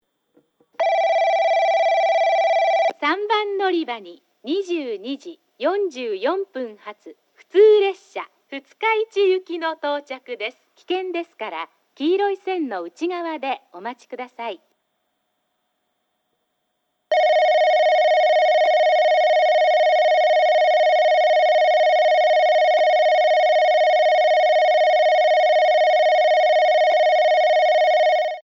スピーカーはJVCラインアレイですが、設置数が多いので収録が行いやすくなっています。
3番のりば接近放送　女声